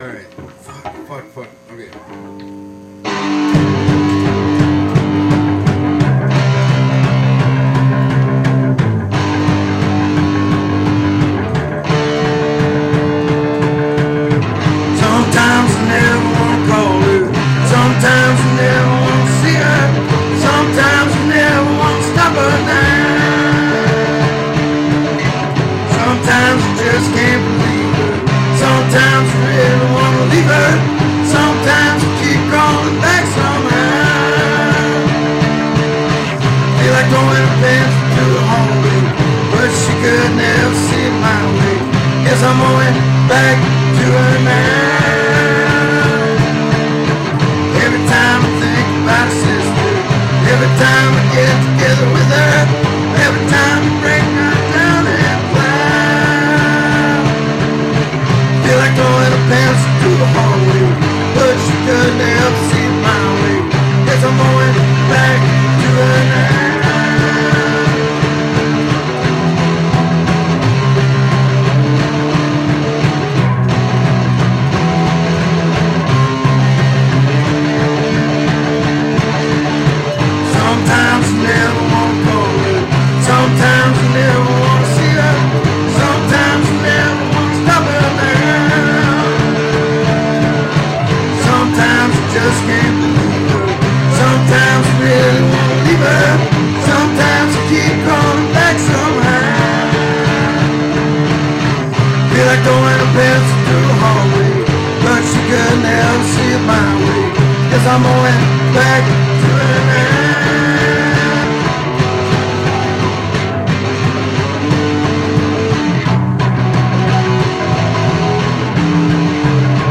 We were trying to decide what song to learn and play when I said, lets just make up a song, lets make a punk rock song.